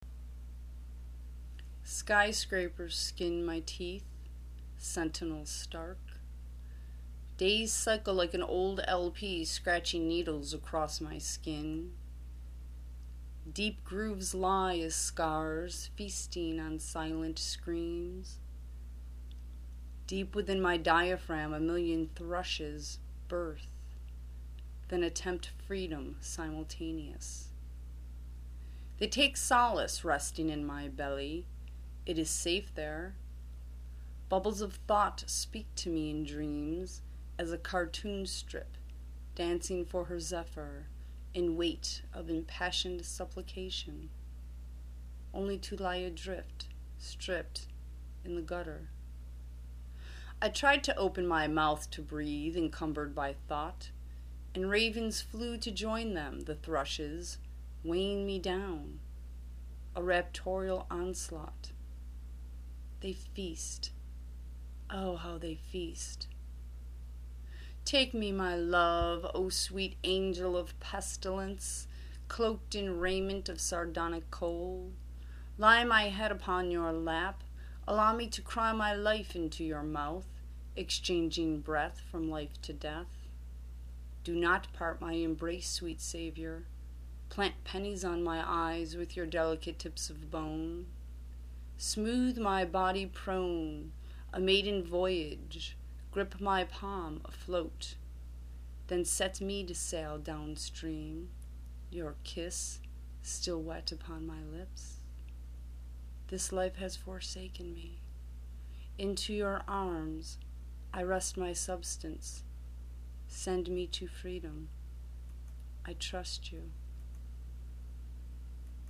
My mind thoughts galloping along with your voice
Return to “Music, Spoken Word & Video”